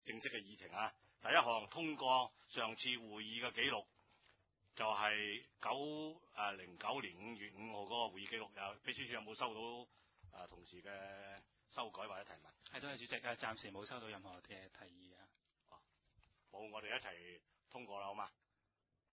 第十次会议议程